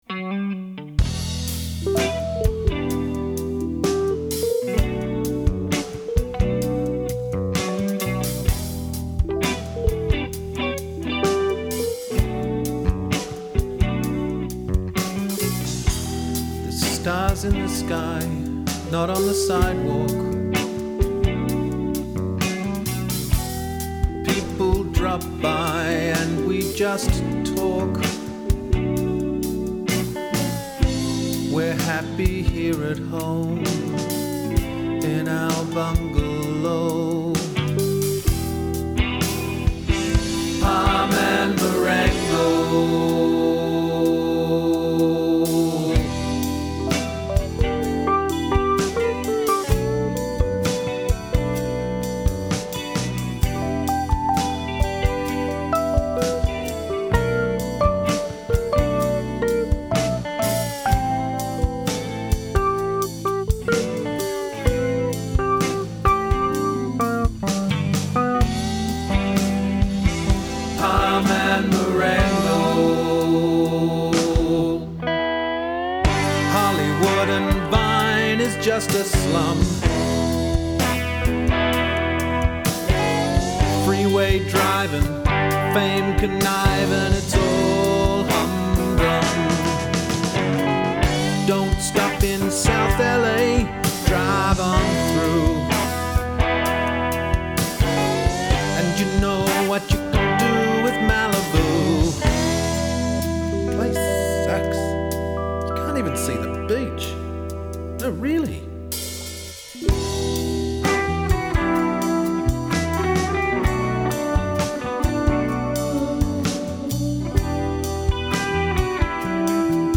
Drums
Bass
Guitars
Lead Vocal
Other Vocals
Keyboards